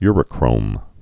(yrə-krōm)